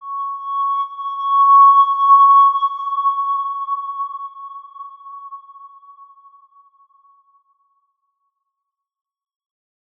X_Windwistle-C#5-mf.wav